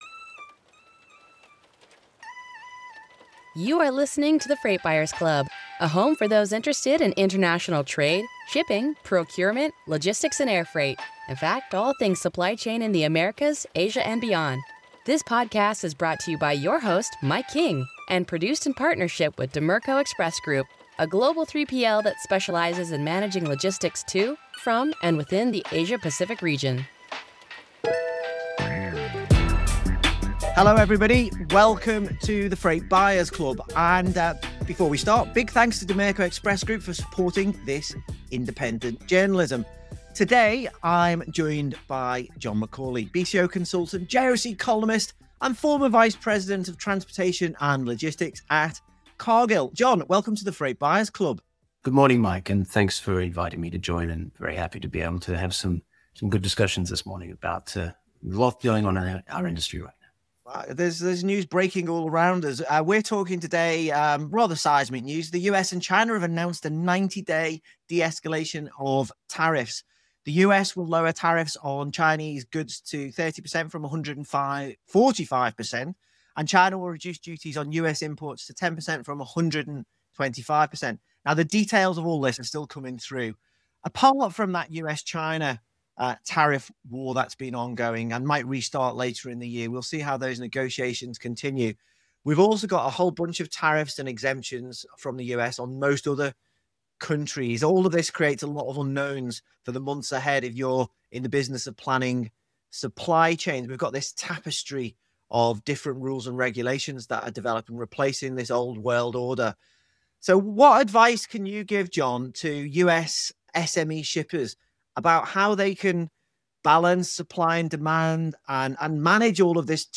In a groundbreaking development, the US and China have announced a 90-day pause in their ongoing tariff war. As businesses and shippers brace for what's next, we dive deep into the implications of this truce for global supply chains. In this episode, host